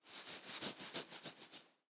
На этой странице вы найдете подборку звуков расчесывания волос – от мягких, едва слышных движений до четких, ритмичных проводок гребнем.
Шуршание почесываемых волос